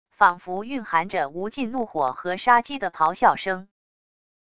仿佛蕴含着无尽怒火和杀机的咆哮声wav音频